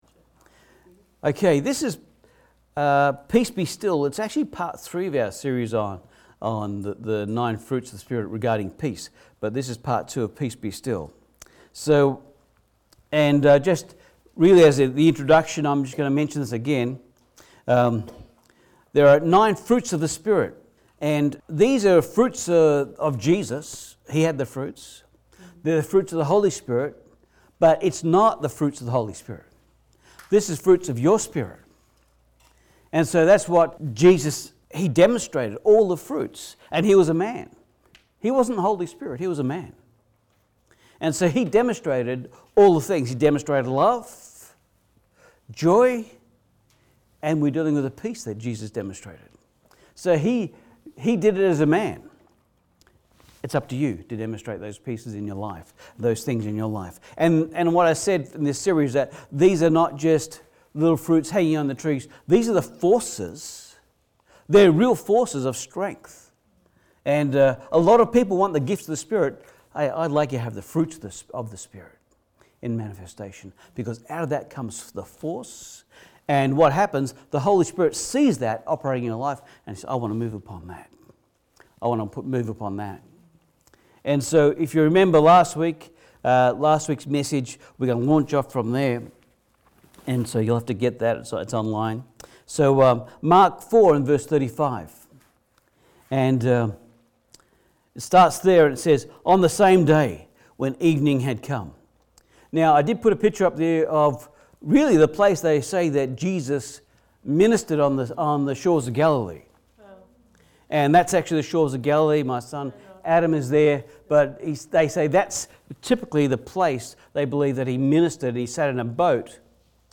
9 Fruits of the Holy Spirit Service Type: Sunday Service Jesus rebukes the storm and uses His peace to calm the sea